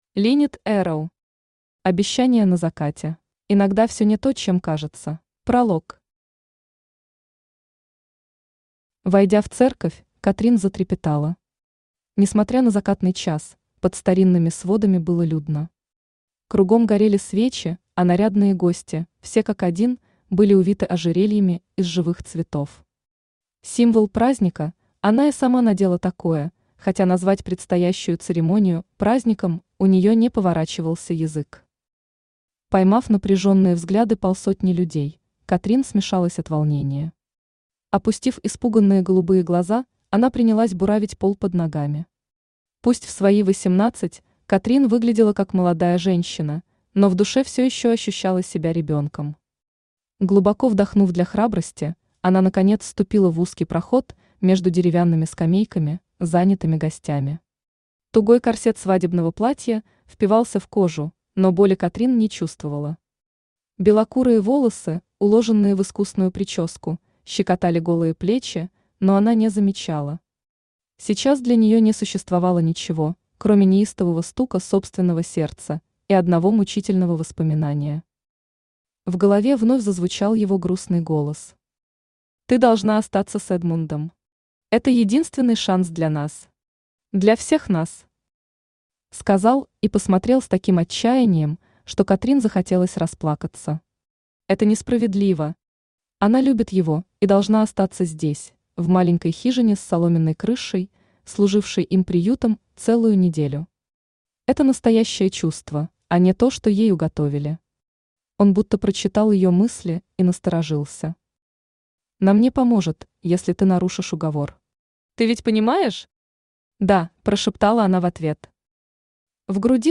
Аудиокнига Обещание на закате | Библиотека аудиокниг
Aудиокнига Обещание на закате Автор Линнет Эрроу Читает аудиокнигу Авточтец ЛитРес.